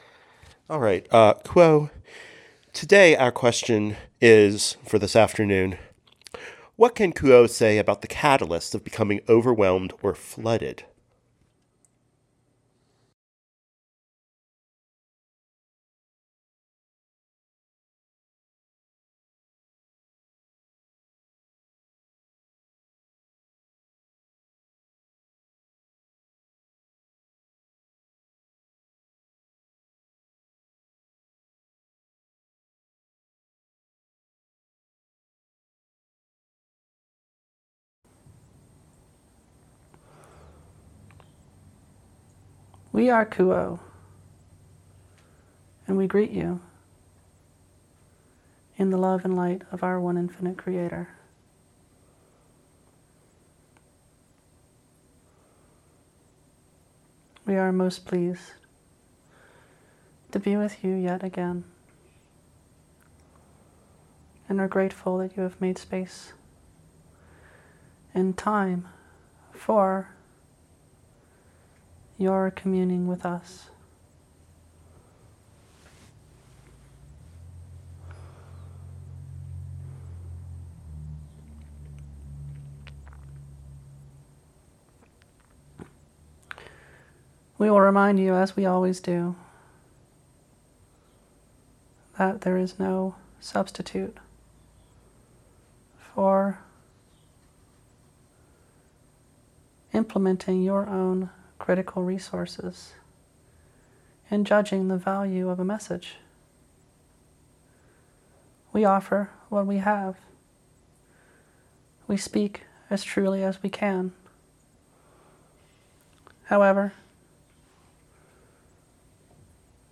In this final session from the Other Selves Working Group’s third channeling intensive, those of Q’uo discuss the diverse circumstances in which an entity can suffer from feeling overwhelmed or flooded.